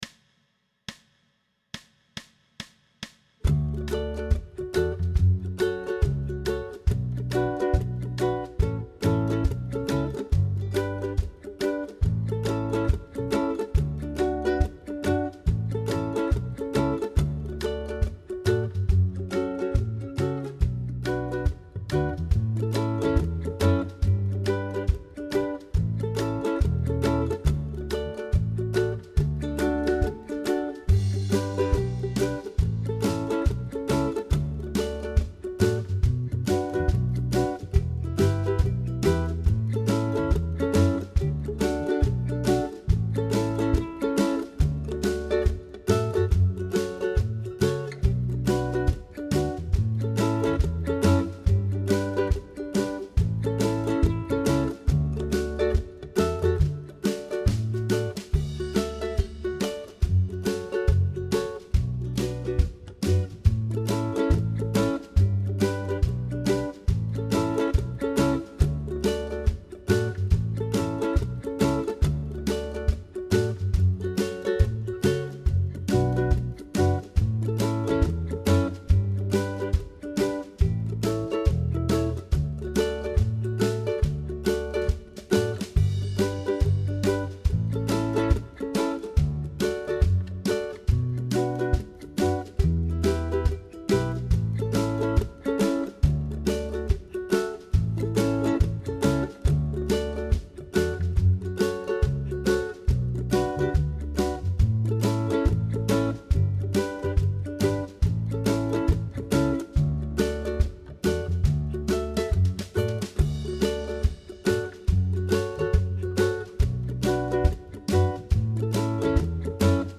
Pop style jam track with Ukulele Rhythm
Tempo: 140BPM
Key of D
ukulele-pop-140-d.mp3